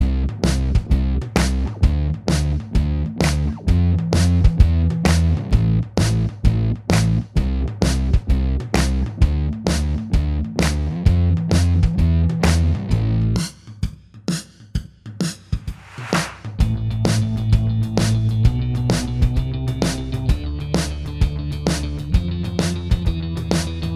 Minus Guitars Pop (2000s) 2:58 Buy £1.50